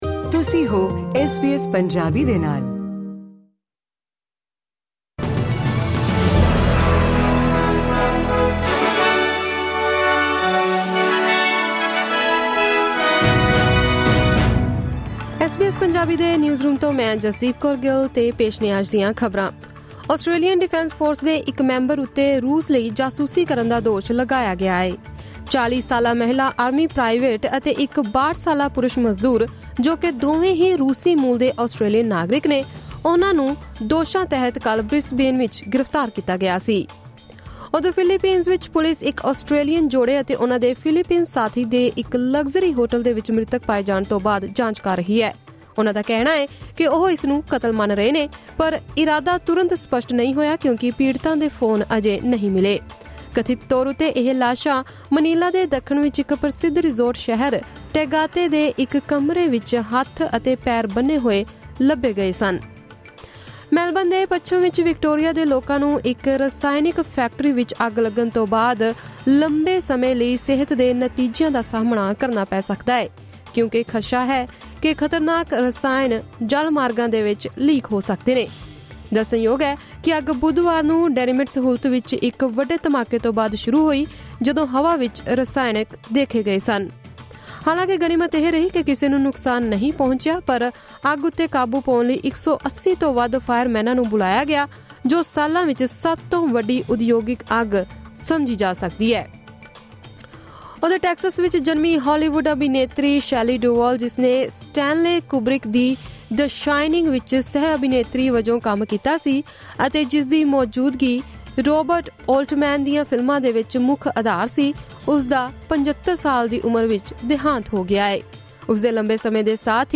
ਐਸ ਬੀ ਐਸ ਪੰਜਾਬੀ ਤੋਂ ਆਸਟ੍ਰੇਲੀਆ ਦੀਆਂ ਮੁੱਖ ਖ਼ਬਰਾਂ: 12 ਜੁਲਾਈ 2024